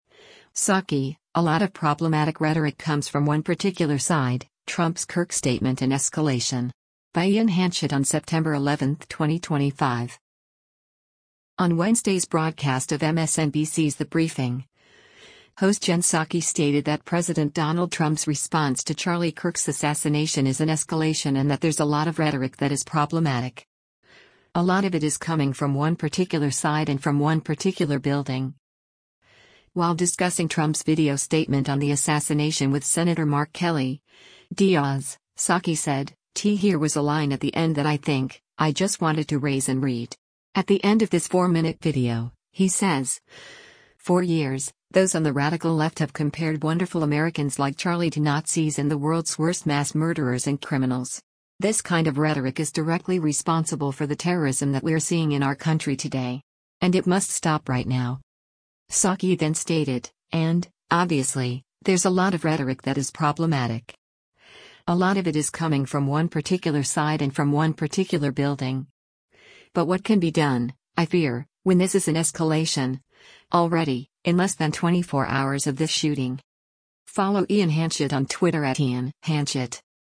On Wednesday’s broadcast of MSNBC’s “The Briefing,” host Jen Psaki stated that President Donald Trump’s response to Charlie Kirk’s assassination is an “escalation” and that “there’s a lot of rhetoric that is problematic. A lot of it is coming from one particular side and from one particular building.”